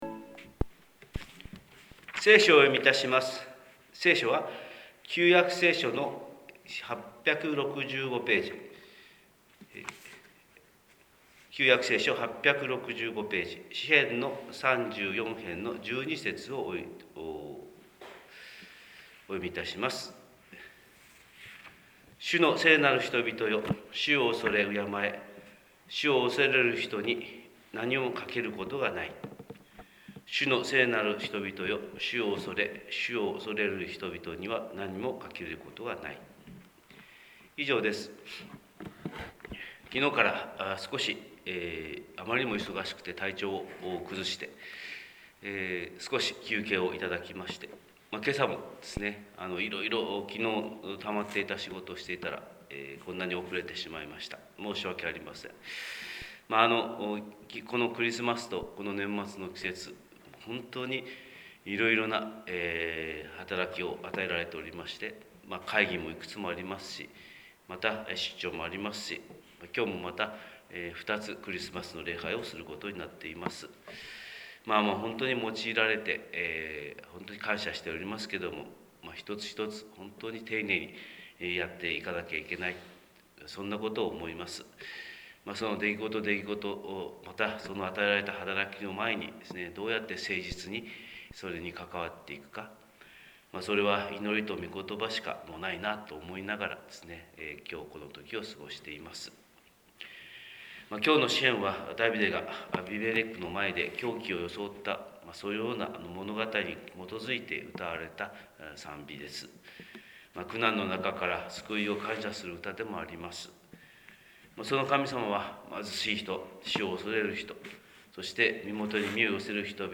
神様の色鉛筆（音声説教）: 広島教会朝礼拝241220
広島教会朝礼拝241220「祈り」